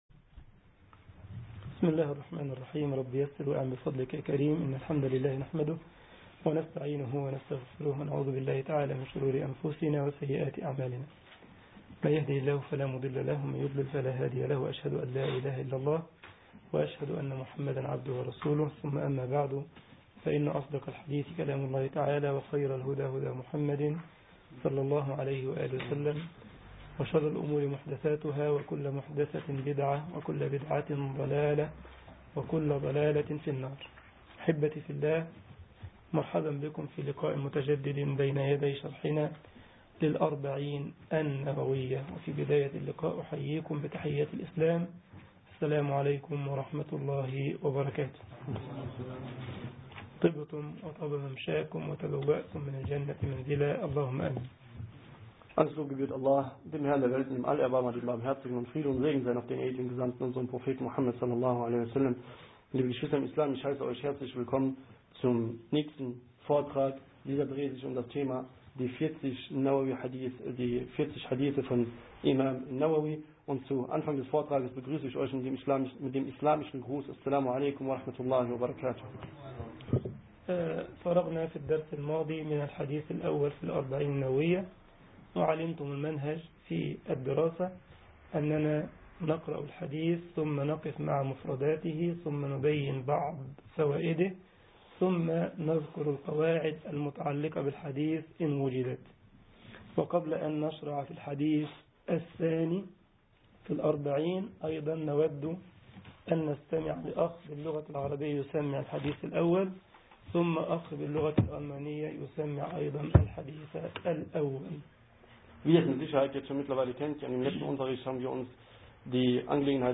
محاضرة
جمعية الشباب المسلمين بسلزبخ ـ ألمانيا